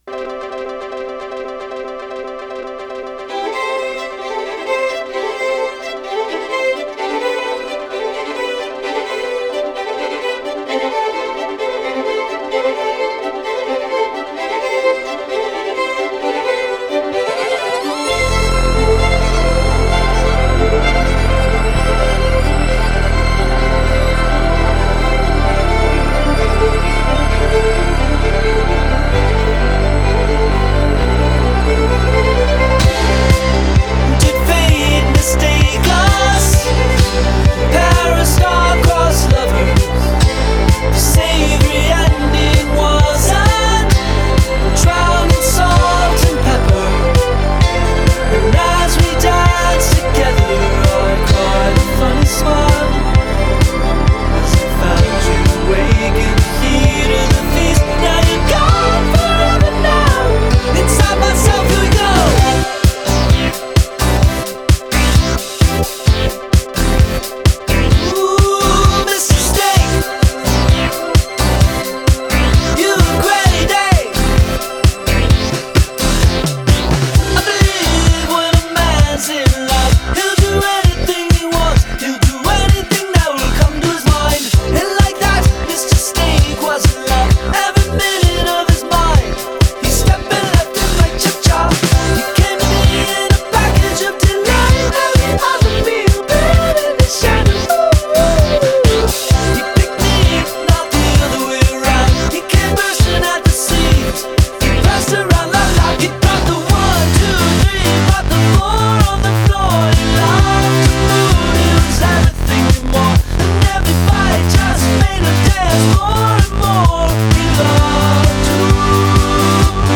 Genre: Indie Pop, Orchestral Pop